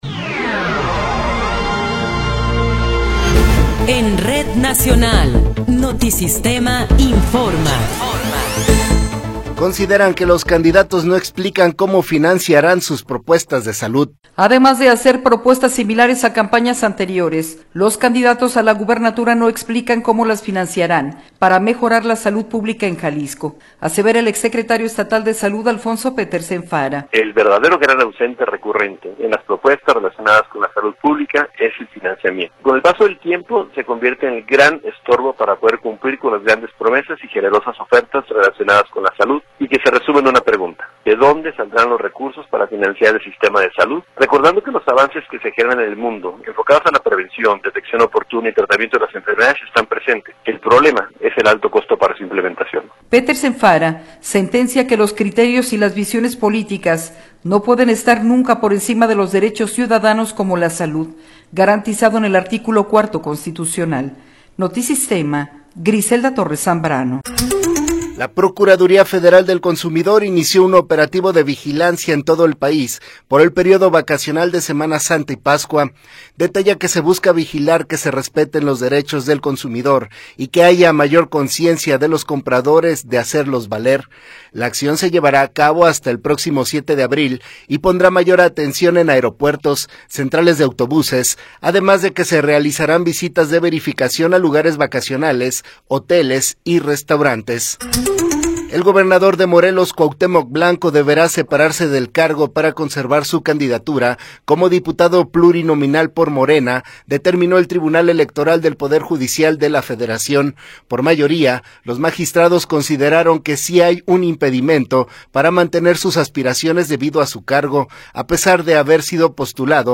Noticiero 15 hrs. – 20 de Marzo de 2024
Resumen informativo Notisistema, la mejor y más completa información cada hora en la hora.